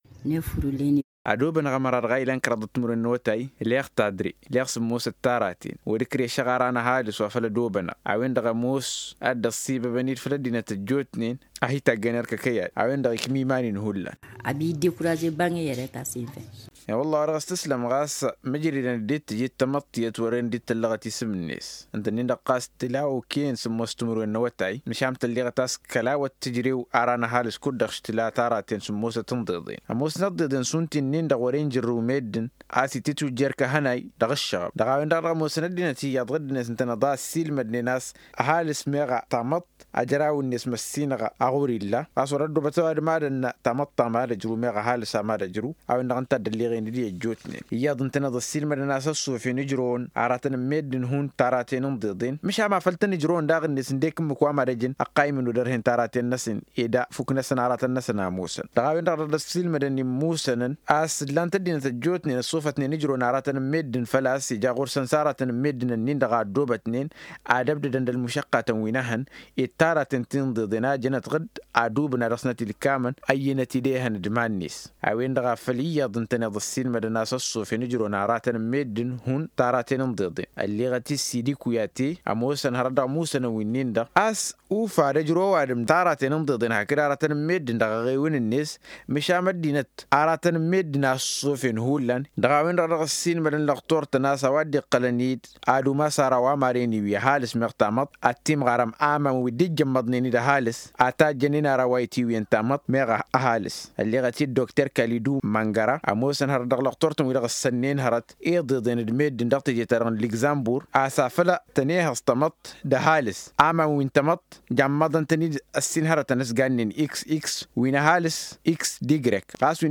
Existe t-il des aliments qu’il faut consommer pour avoir un garçon ou une fille ? Des éléments de réponses dans ce reportage .